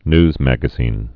(nzmăgə-zēn, nyz-)